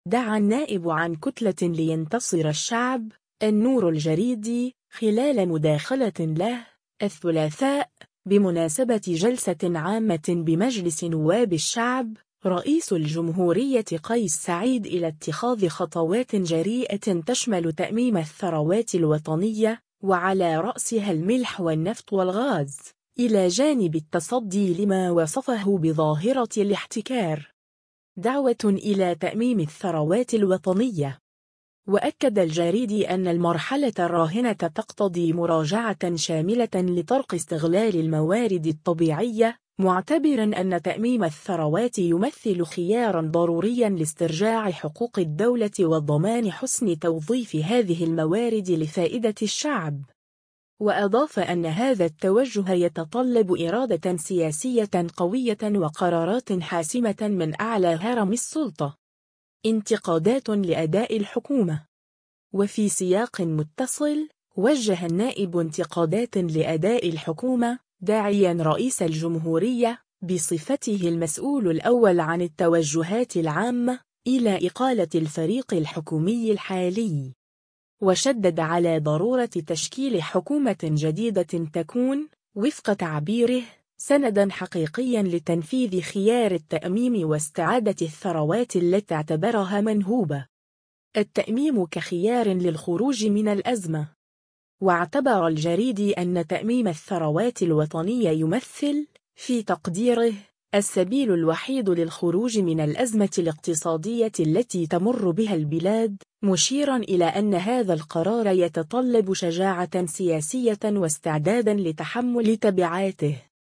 دعا النائب عن كتلة “لينتصر الشعب”، النوري الجريدي، خلال مداخلة له، الثلاثاء، بمناسبة جلسة عامة بمجلس نواب الشعب، رئيس الجمهورية قيس سعيّد إلى اتخاذ خطوات جريئة تشمل تأميم الثروات الوطنية، وعلى رأسها الملح والنفط والغاز، إلى جانب التصدي لما وصفه بظاهرة الاحتكار.